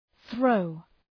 Προφορά
{ɵrəʋ}